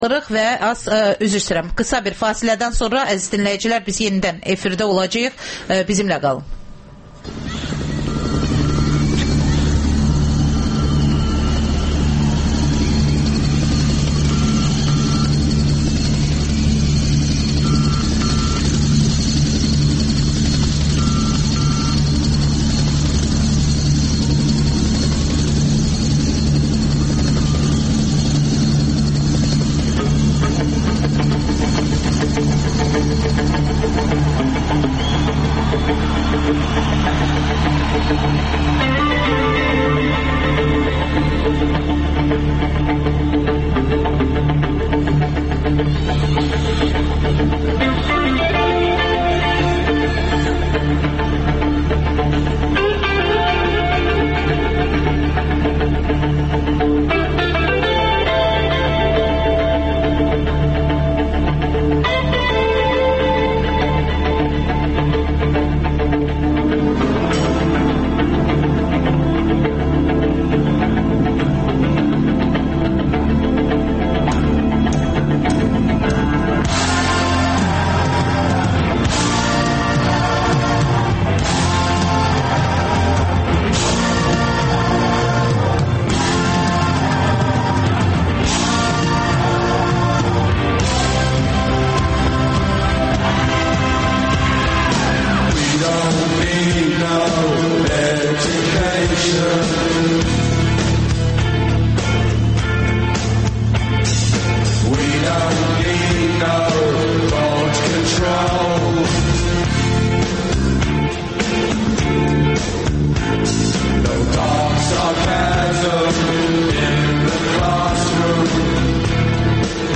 AzadlıqRadiosunun müxbirləri məntəqə-məntəqə dolaşıb səsvermənin gedişini xəbərləyirlər.